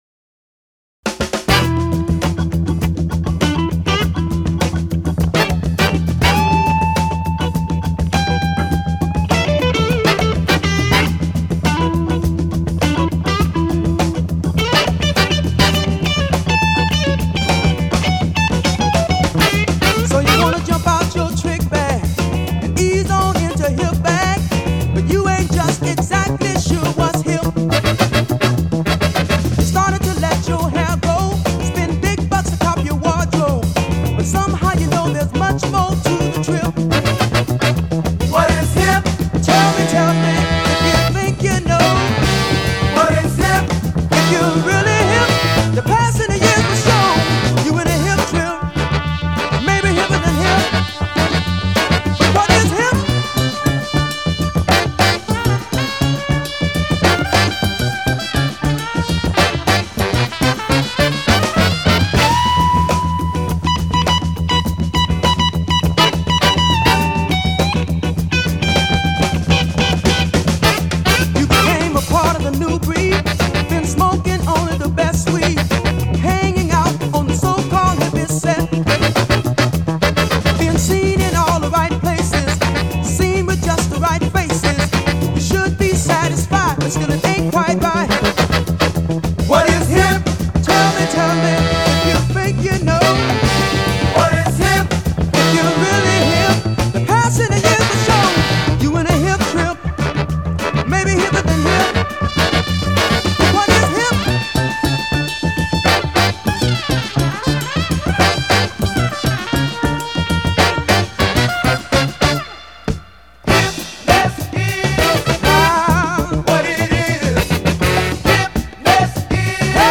Musikhistoriegruppe_A-3:   Funk          GRUPPERAPPORT